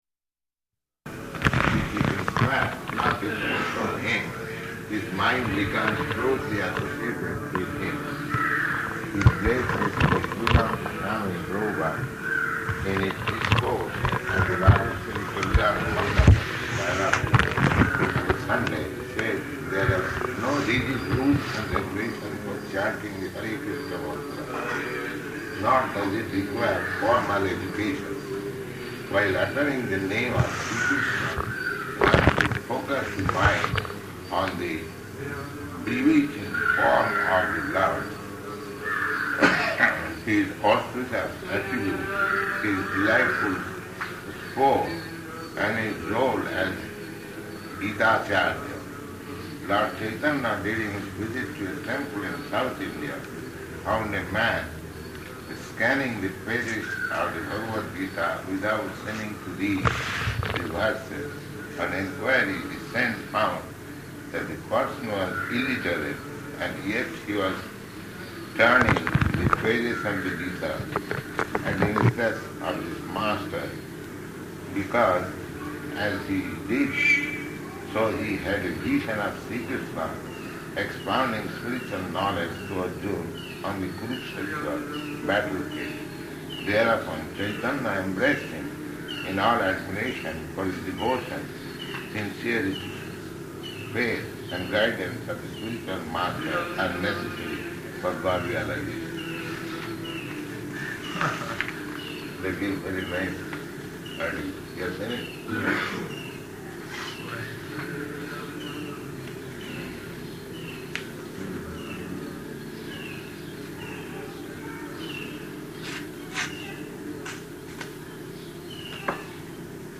Room Conversation
Room Conversation --:-- --:-- Type: Conversation Dated: February 15th 1972 Location: Madras Audio file: 720215R1.MAD.mp3 Prabhupāda: [reading:] "...